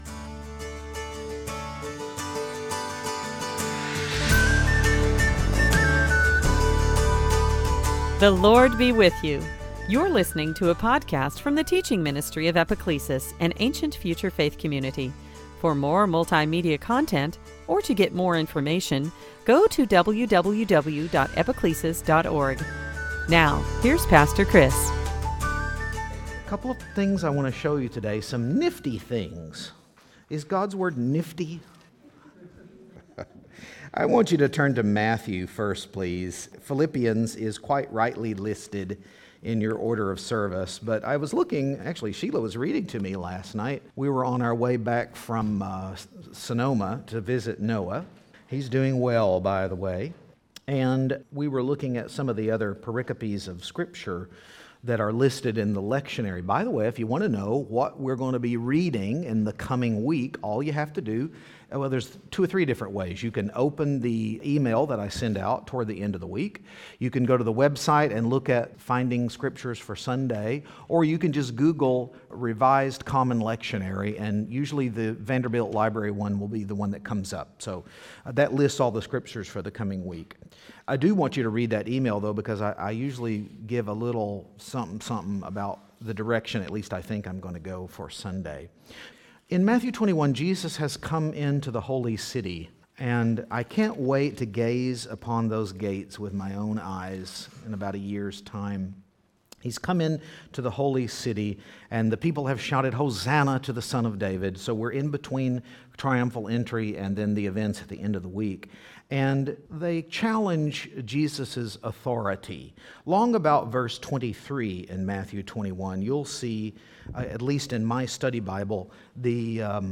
Preacher
It’s a poetic passage that might have even been a hymn of the early church.
Service Type: Season after Pentecost